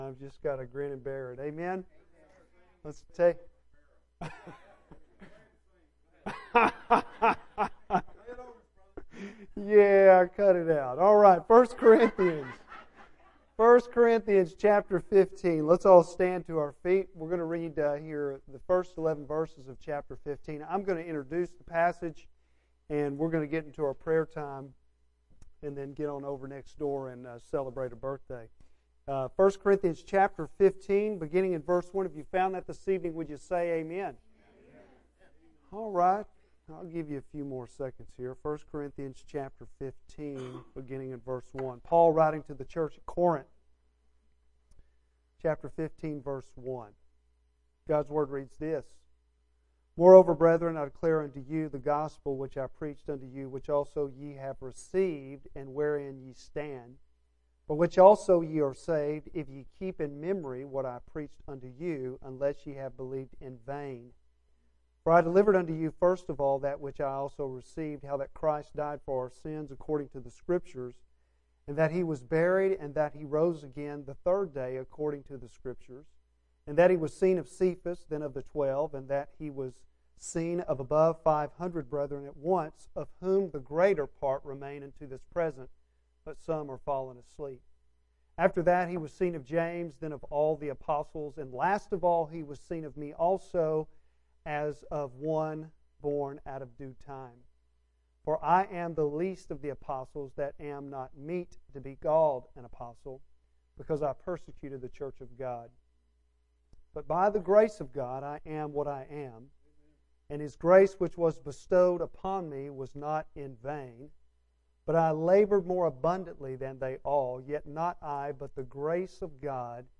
Bible Text: I Corinthians 15:1-11 | Preacher